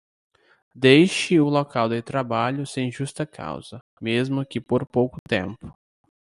Pronounced as (IPA)
/tɾaˈba.ʎu/